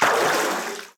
WaterSplash_Out_Short3_shorter.ogg